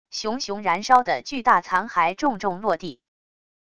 熊熊燃烧的巨大残骸重重落地wav音频